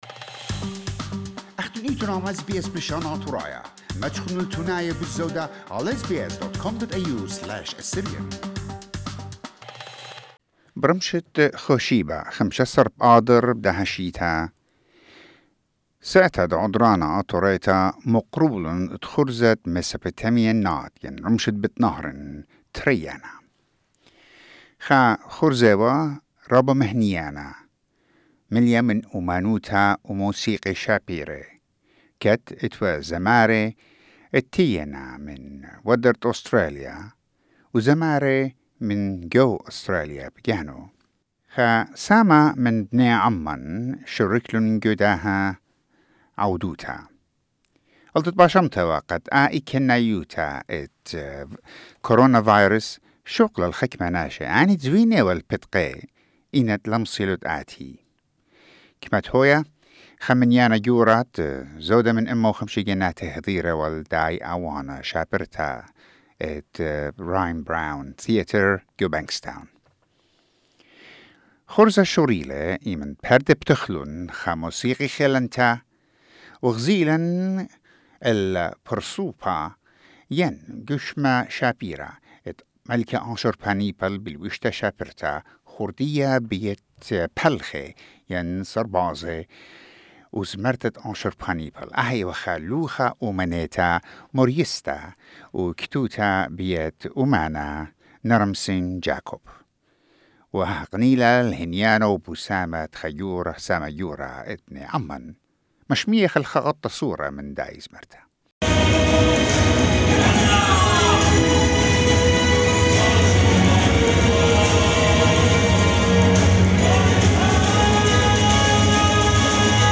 Despite the fear and anxiety of the corona virus, more than hundred and fifty people attended the concert.